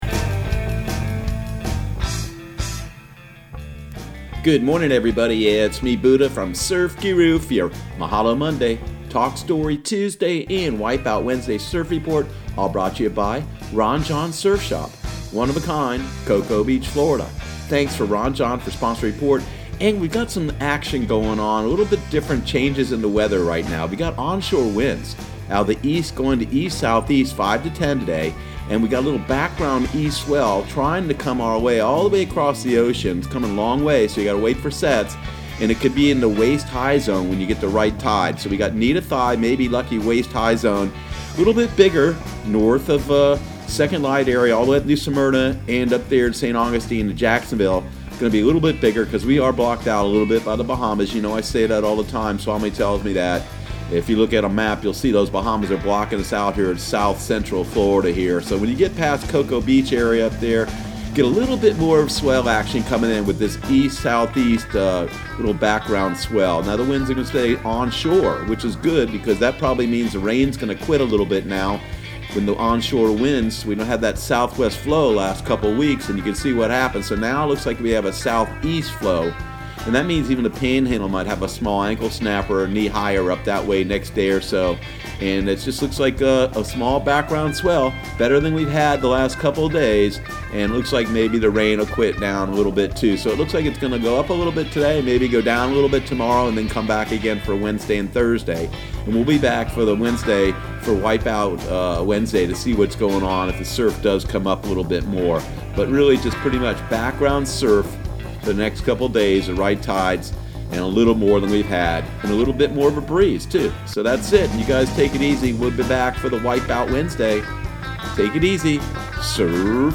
Surf Guru Surf Report and Forecast 08/19/2019 Audio surf report and surf forecast on August 19 for Central Florida and the Southeast.